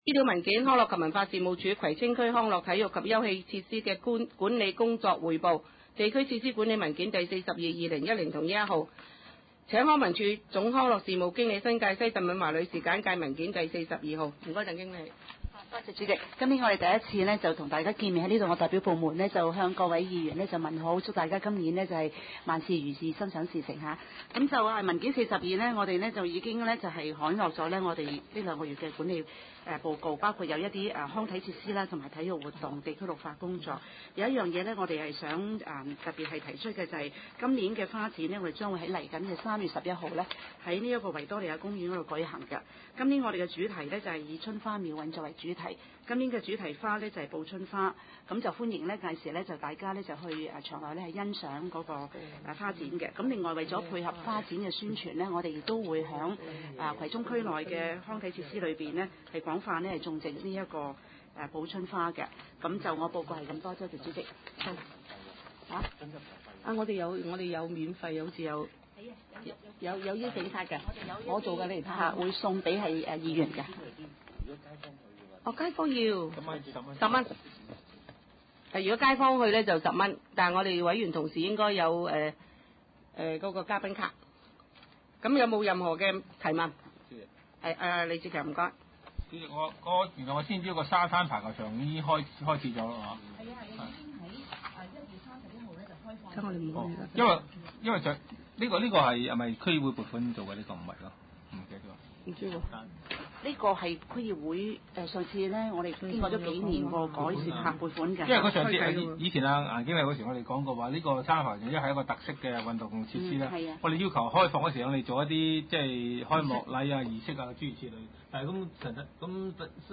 第六次會議(一零/一一)
葵青民政事務處會議室